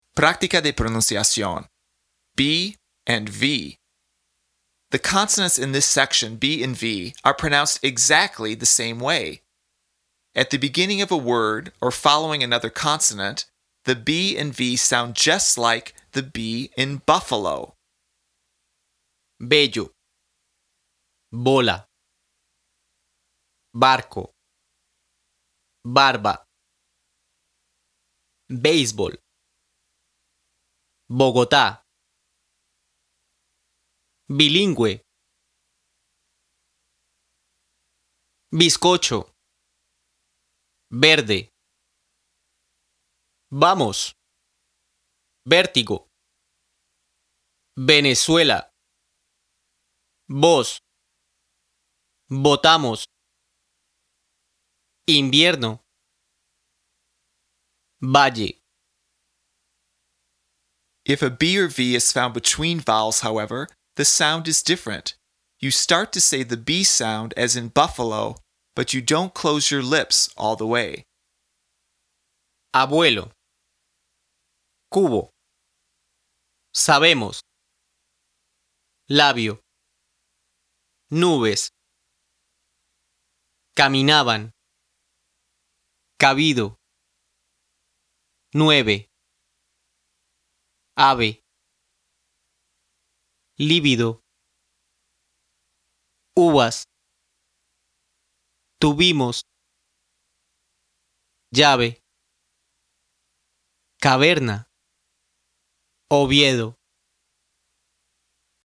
PRÁCTICA DE PRONUNCIACIÓN
The consonants in this section — “b” and “v” — are pronounced exactly the same way!
At the beginning of a word or following another consonant, the “b” and “v” sound just like the “b” in “buffalo.”
If a “b” or “v” is found between vowels, however, the sound is different.